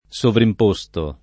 DOP: Dizionario di Ortografia e Pronunzia della lingua italiana
sovrimposto [ S ovrimp 1S to ]